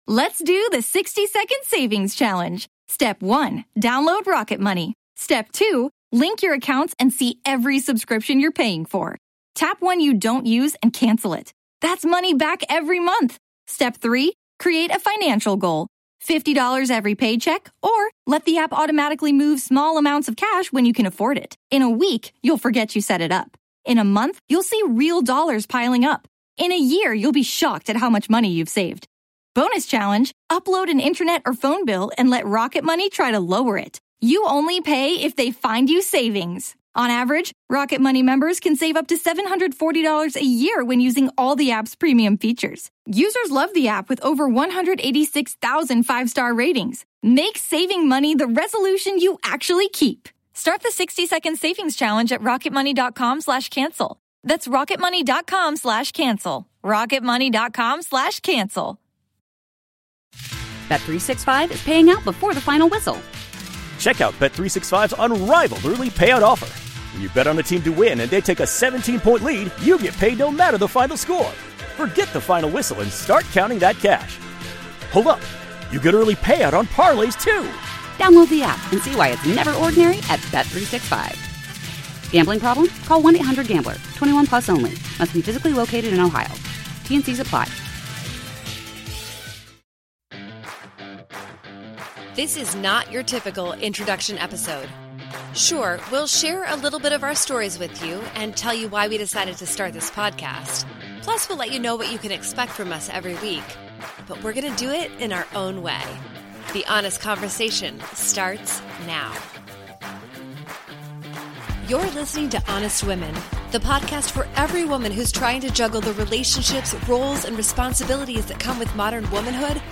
Disclaimer: Please note while this podcast features two therapists, and may feel very therapeutic, this is not therapy!